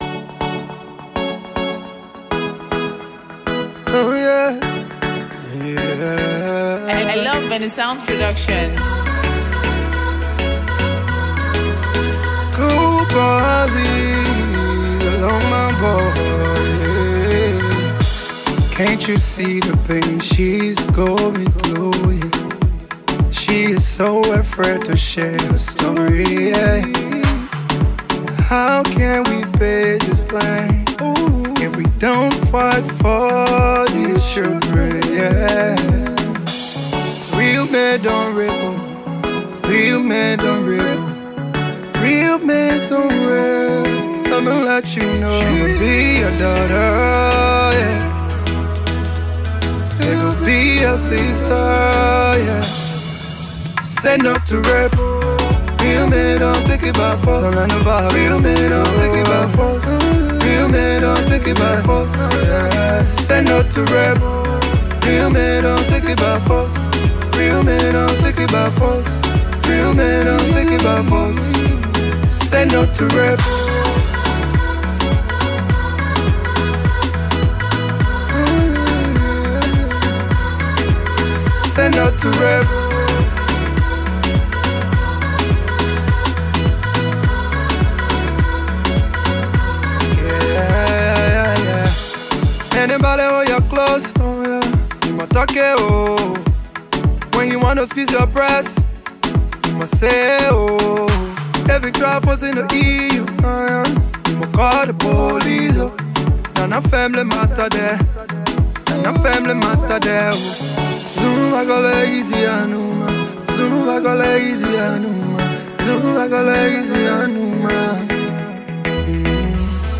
/ Afrobeats / By